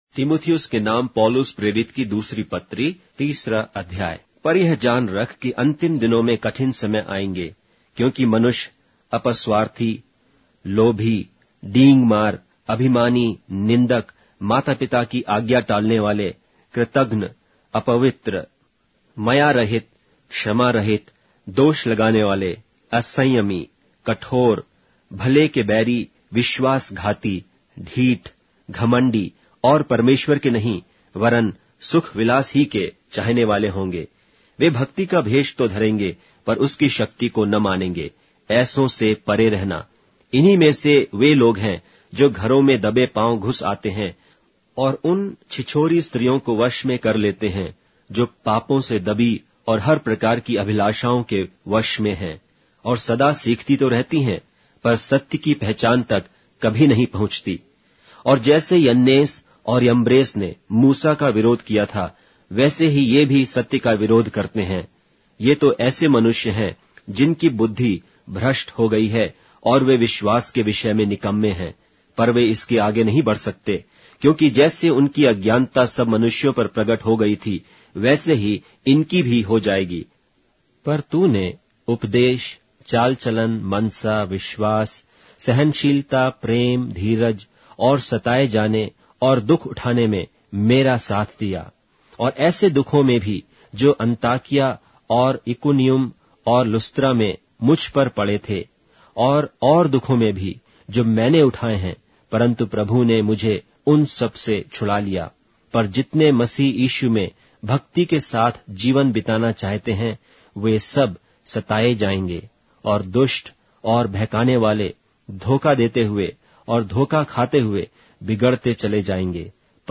Hindi Audio Bible - 2-Timothy 4 in Bnv bible version